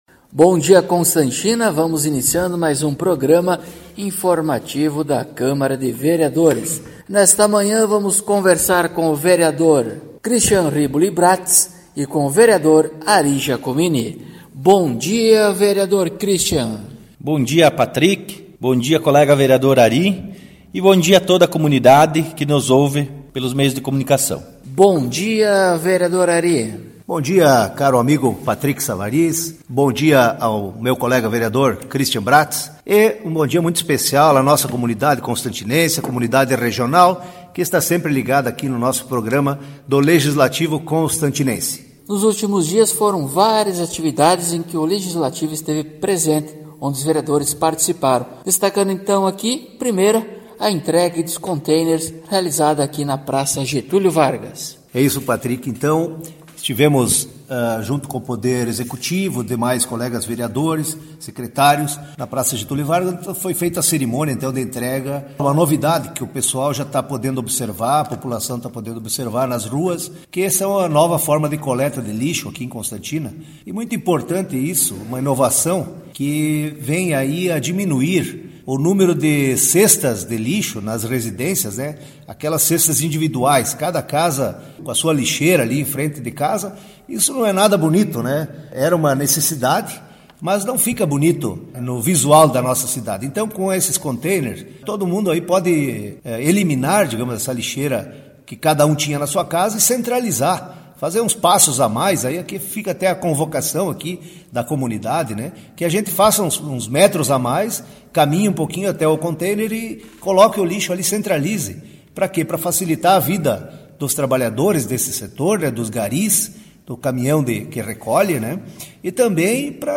Acompanhe o programa informativo da câmara de vereadores de Constantina com o Vereador Ari Dirceu Giacomini e o Vereador Cristian Bratz.